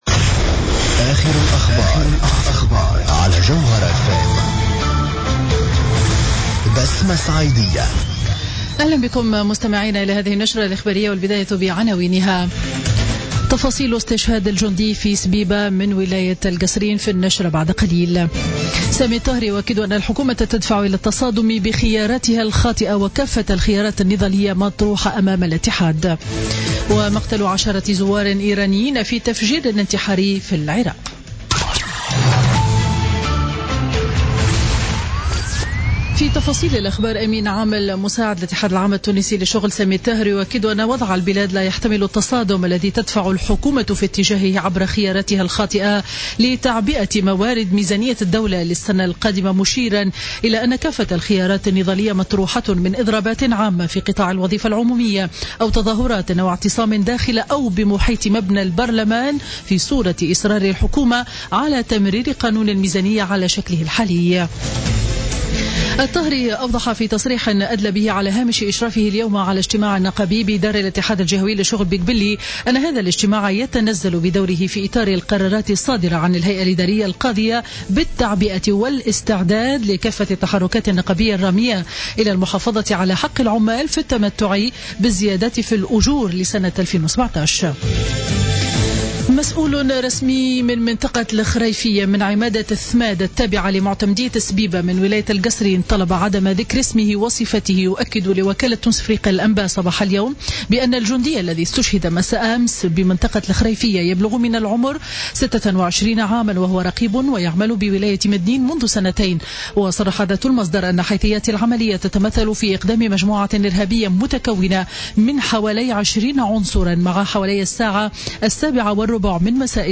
نشرة أخبار منتصف النهار ليوم الأحد 6 نوفمبر 2016